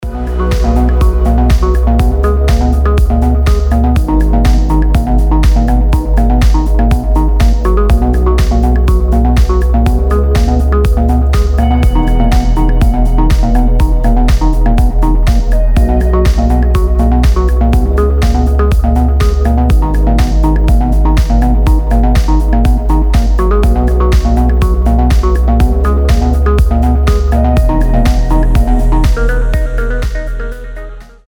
deep house
dance
Electronic
EDM
без слов
релакс
Chill
Приятная музыка без слова, можно и на будильник поставить